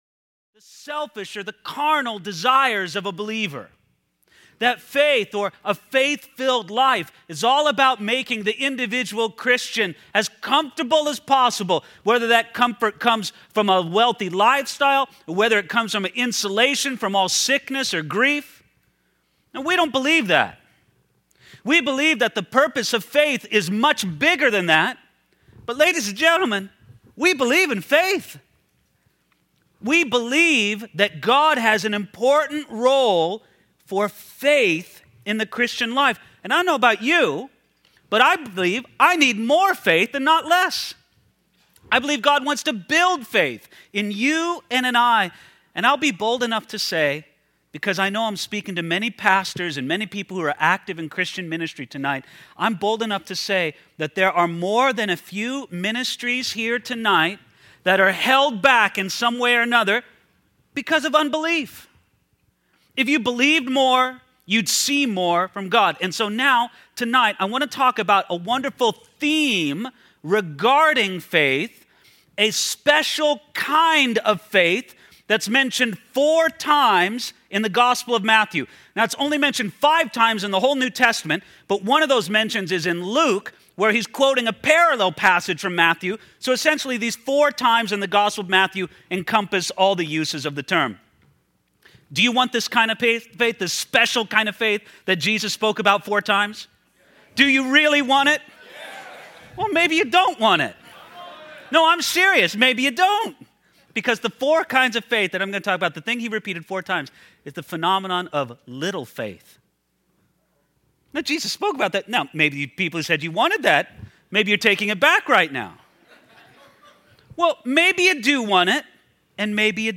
2012 Home » Sermons » Session 10 Share Facebook Twitter LinkedIn Email Topics